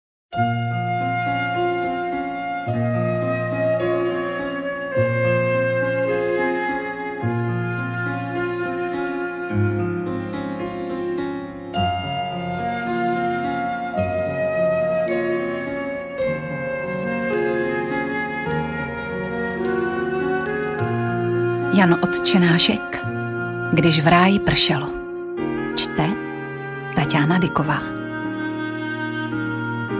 Audiokniha
Tatiana Dyková ve své interpretaci tohoto proslulého románu nesmírně citlivě a účinně zdůraznila něhu, humor a láskyplnou sílu milostné dvojice.